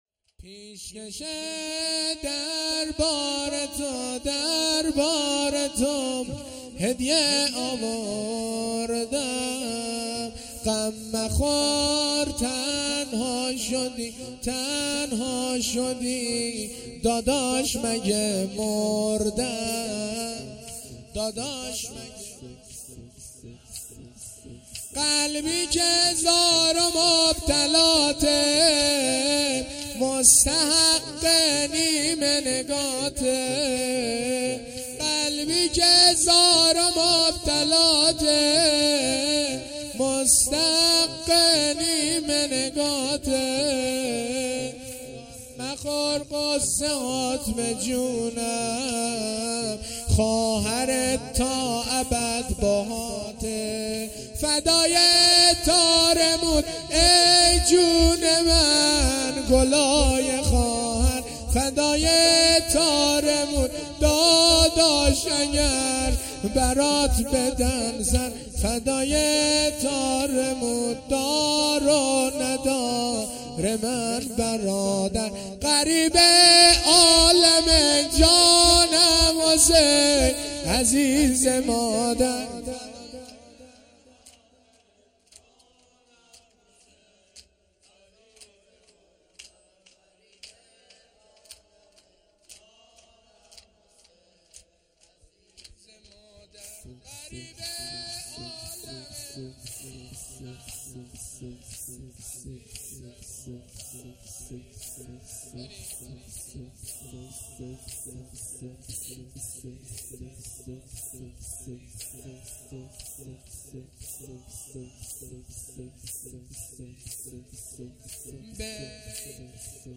محرم الحرام ۱۴۴۳